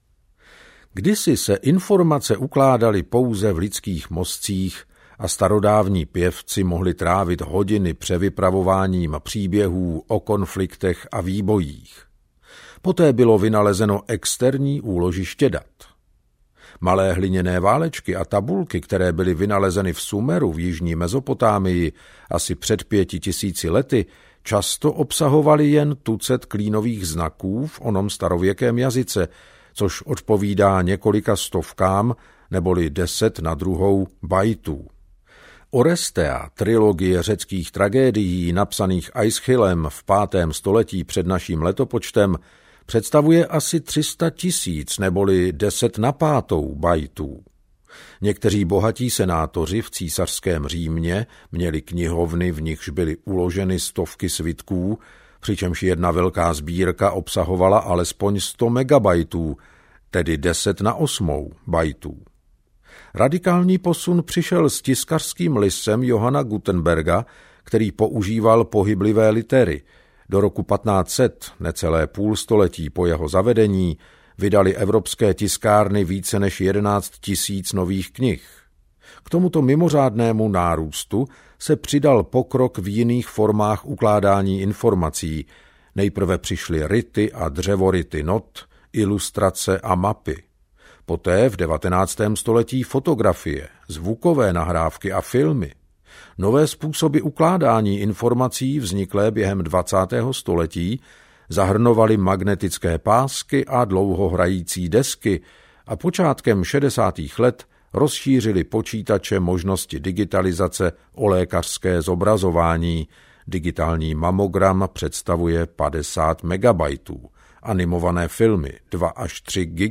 Audiokniha Čísla nelžou - Václav Smil | ProgresGuru